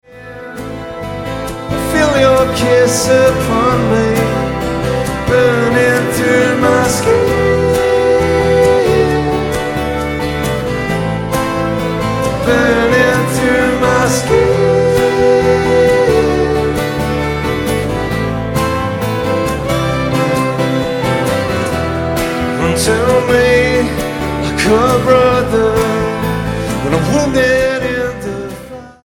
STYLE: Pop
minimal percussion and strings